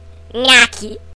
有趣的咬合
描述：一个有趣的咬声！
Tag: 薯片 口香糖 牙齿 嘴巴 用力咀嚼 紧缩Y 食品 紧缩 吃饭 零食